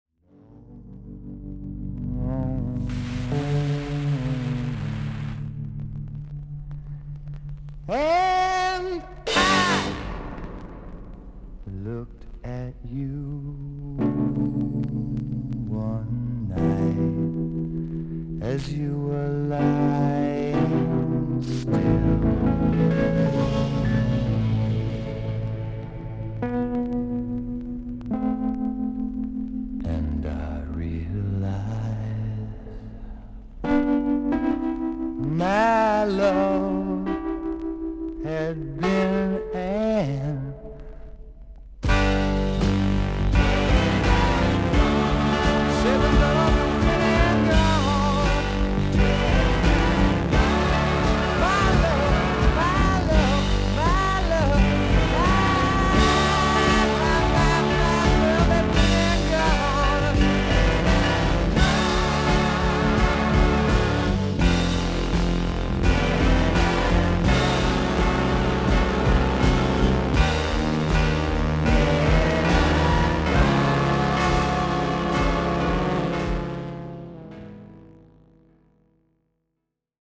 A5中盤にサーッと3秒ほどノイズあり。
少々サーフィス・ノイズあり。クリアな音です。
シンガー・ソング・ライター/キーボーディスト。